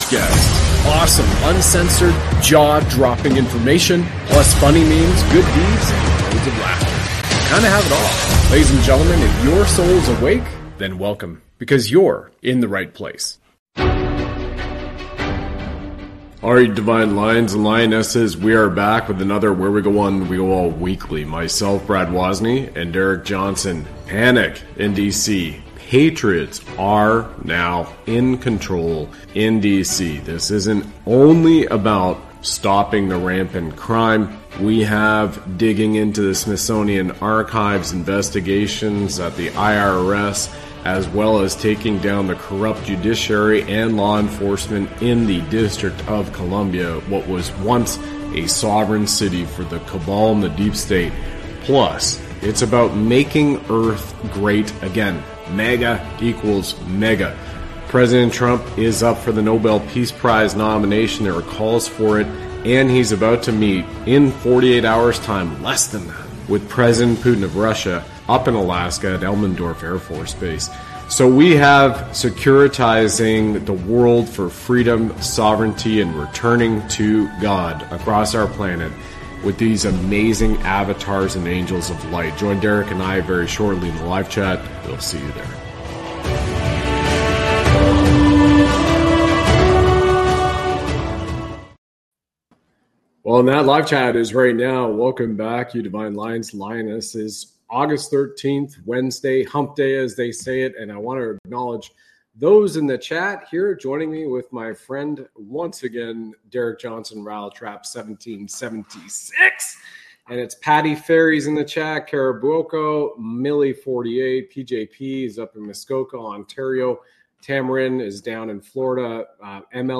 They share personal updates and engage with their audience in a live chat. The conversation wraps up with a look at recent events in Canada, including new restrictions and a viral video.